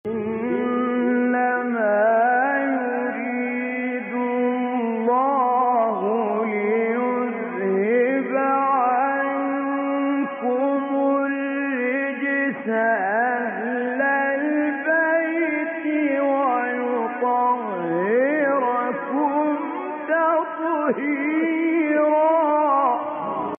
به بهانه سالروز تخریب بقاع متبرکه بقیع کرسی تلاوت مجازی با محوریت آیه تطهیر را با صدای راغب مصطفی غلوش، کامل یوسف البهتیمی، شعبان عبدالعزیز صیاد و سیدمتولی عبدالعال از قاریان شهیر جهان اسلام می‌شنوید.
تلاوت آیه تطهیر با صوت سید متولی عبدالعال